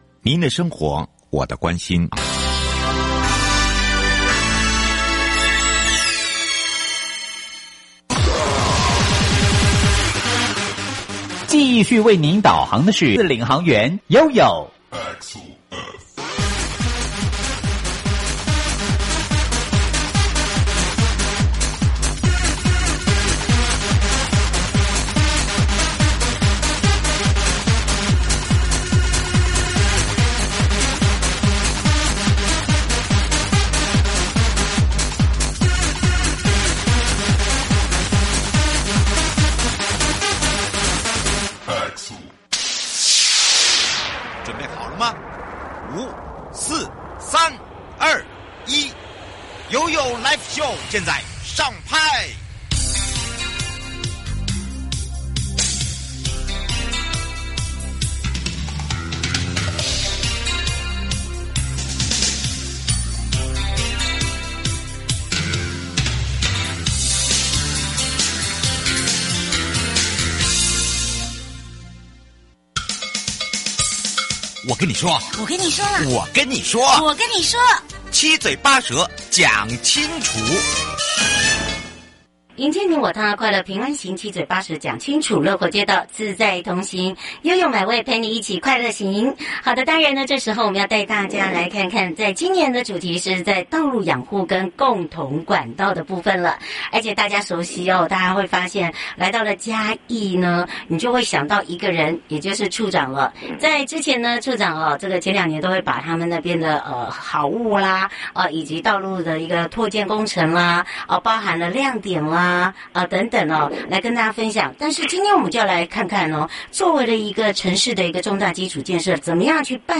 節目內容： 嘉義縣政府建設處-郭良江處長 (上集)